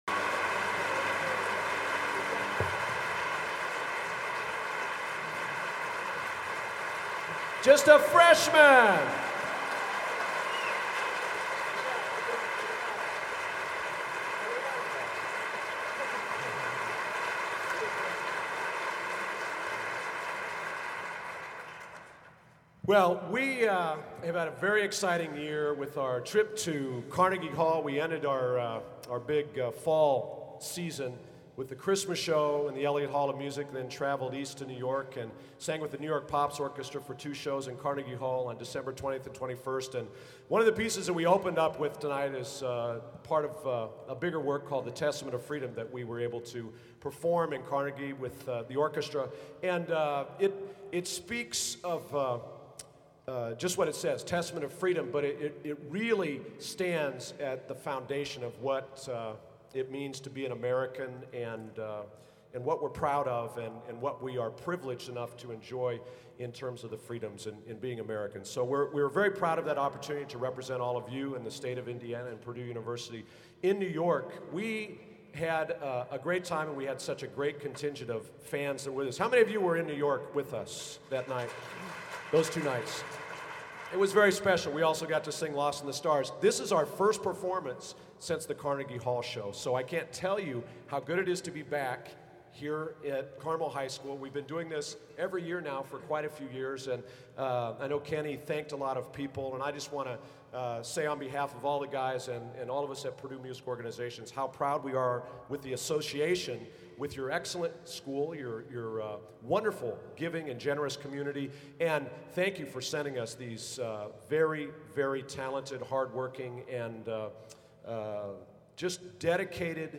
Location: Carmel High School, Carmel, IN
Genre: | Type: Director intros, emceeing